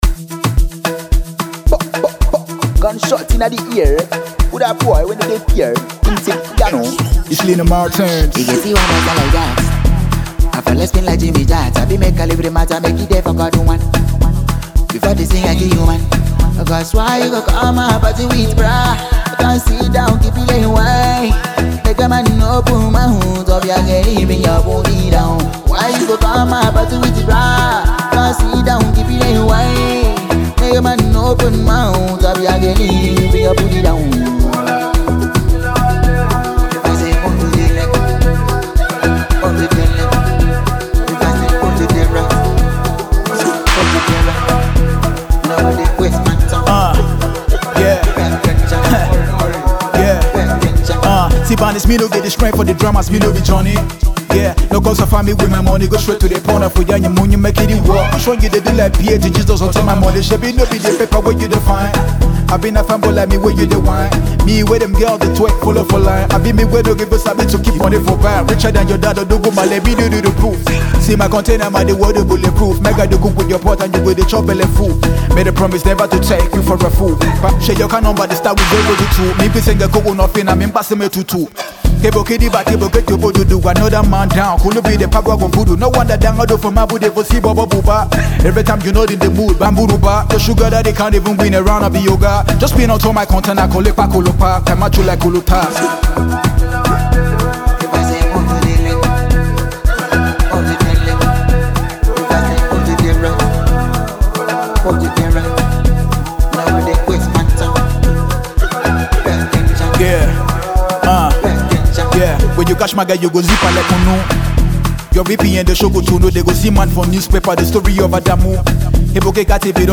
fast emerging indigenous rapper